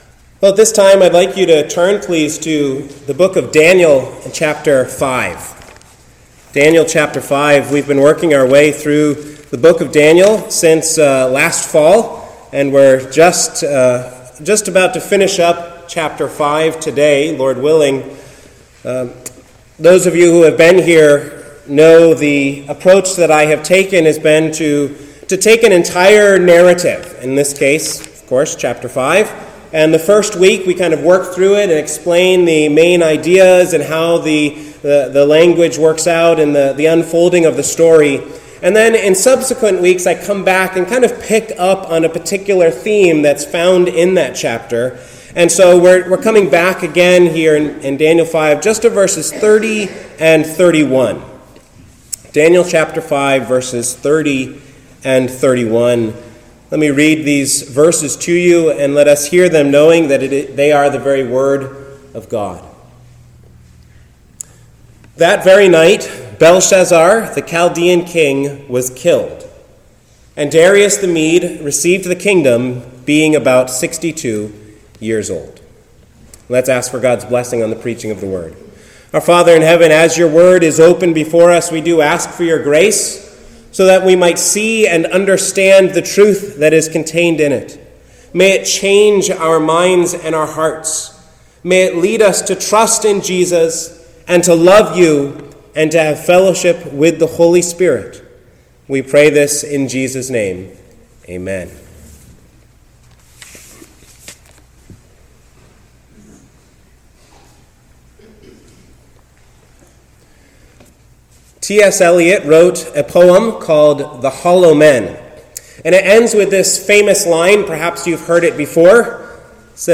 The Fall of Babylon | SermonAudio Broadcaster is Live View the Live Stream Share this sermon Disabled by adblocker Copy URL Copied!